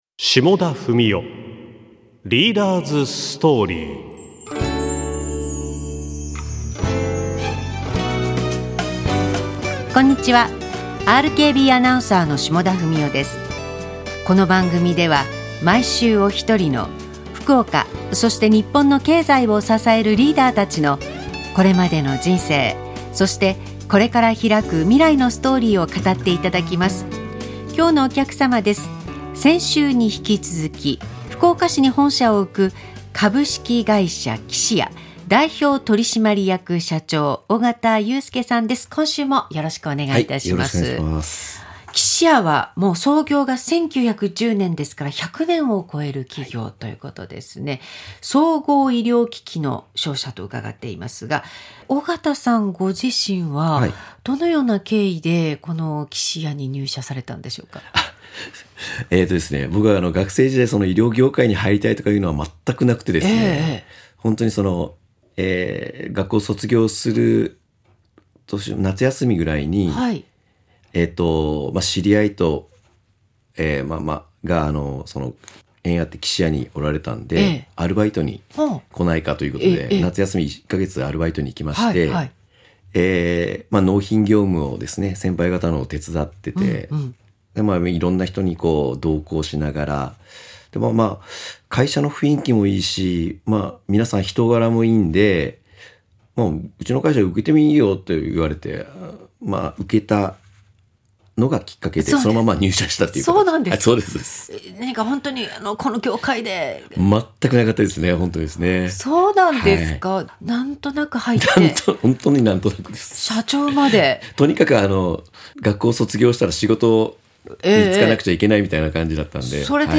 ラジオ